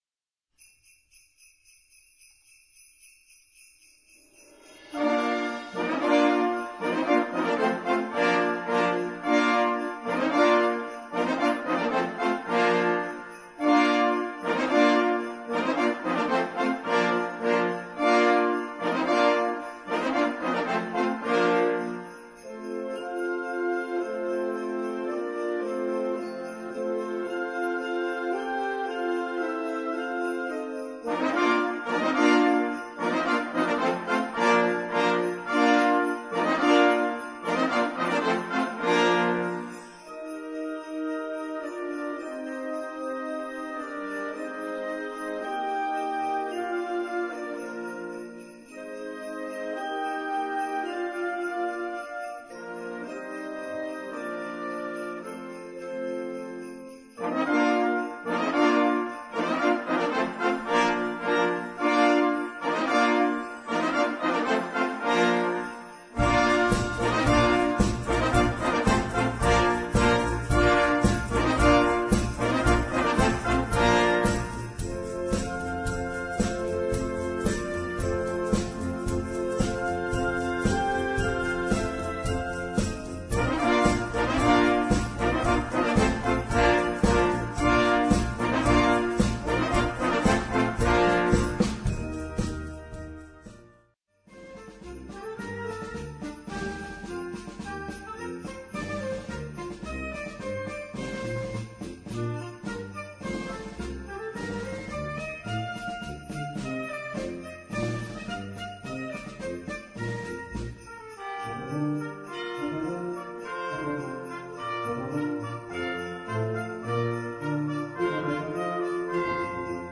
Gattung: Weihnachtstitel
Besetzung: Blasorchester